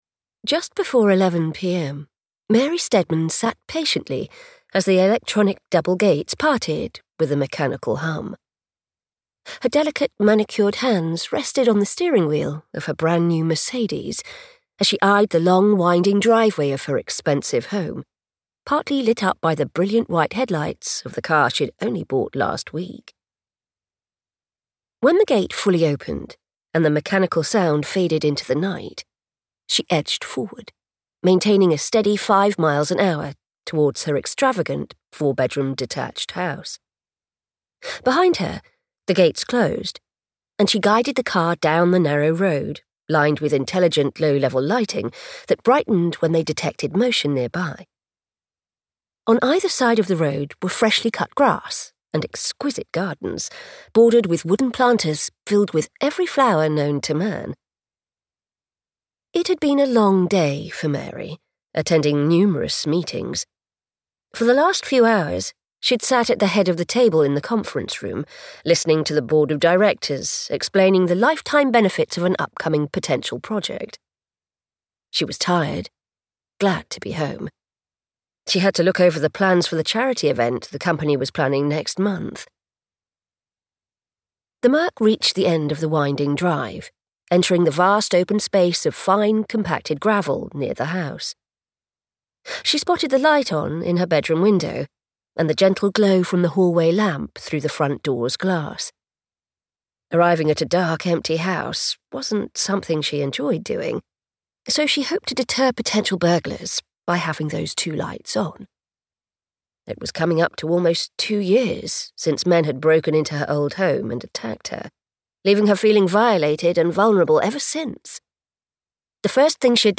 Taken from Her Home - Detective April Fisher Thrillers, Book 2 - Vibrance Press Audiobooks - Vibrance Press Audiobooks